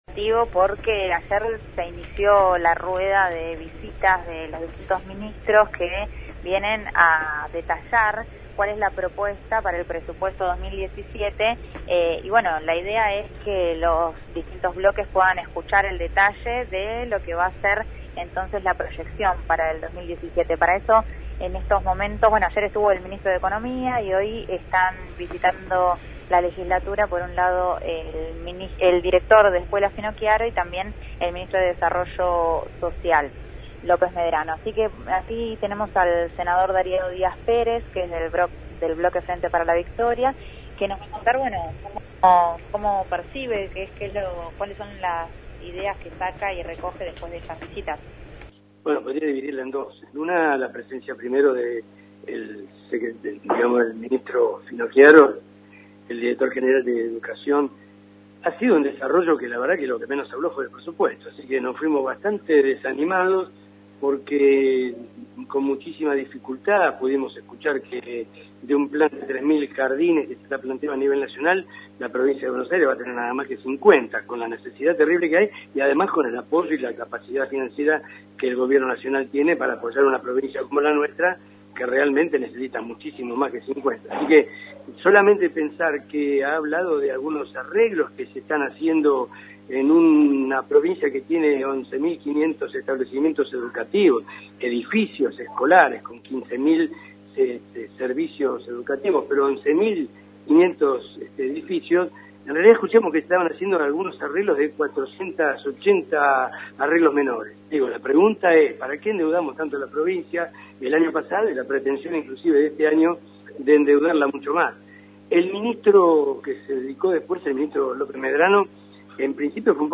desde la Legislatura con la visita de los Ministros Alejandro Finocchiaro (Educación) y Santiago López Medrano (Desarrollo social) en el debate por el presupuesto provincial para 2017.